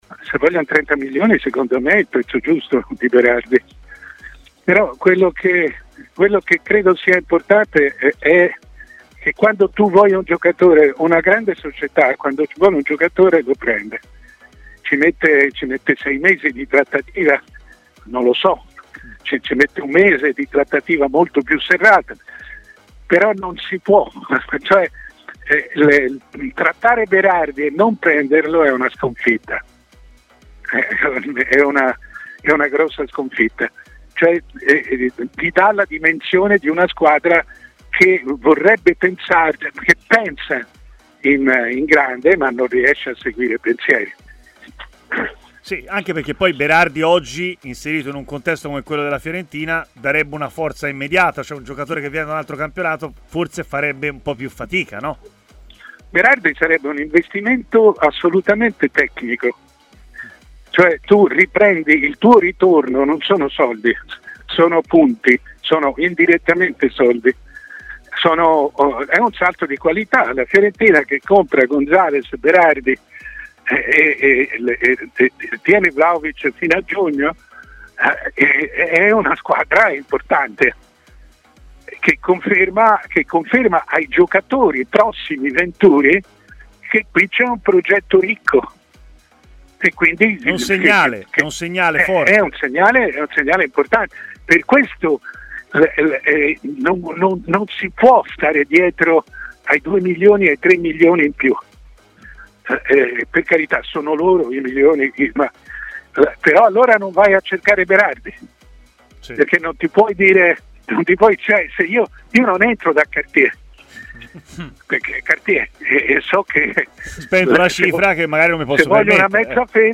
Il direttore Mario Sconcerti, durante la trasmissione Stadio Aperto di TMW Radio, ha parlato anche dell'operazione che potrebbe portare in viola Domenico Berardi.